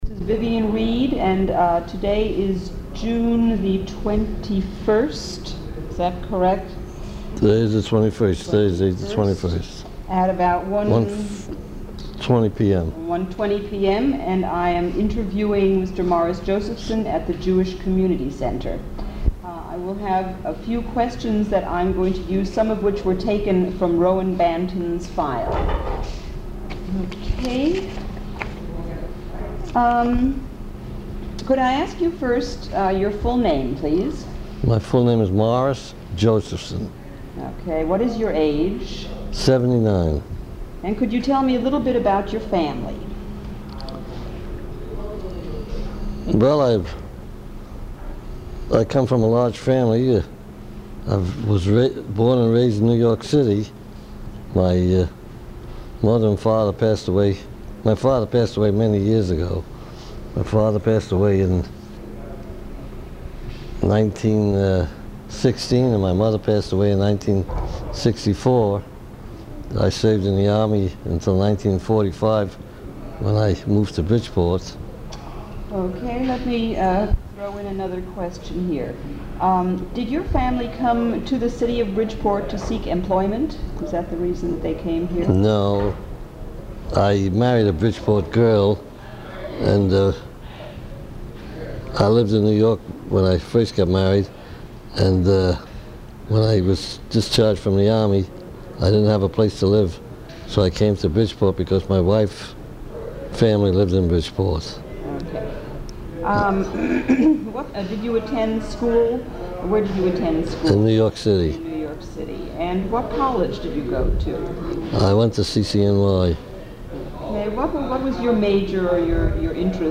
Bridgeport Living History : Oral Histories Conducted by Bridgeport Youth in 1984